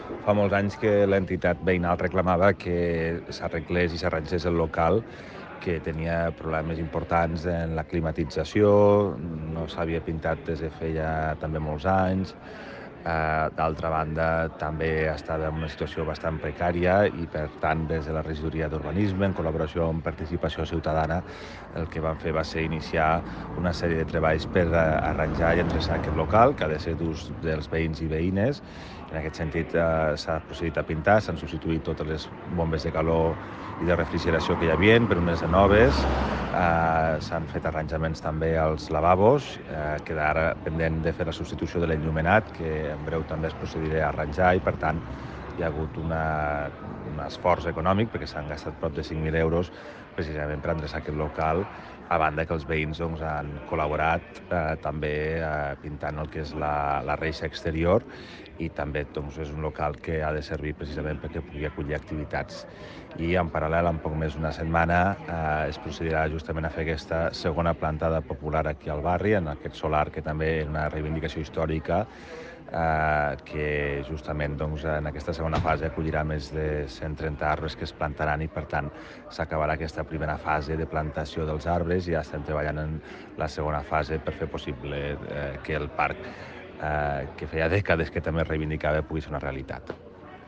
tall-de-veu-toni-postius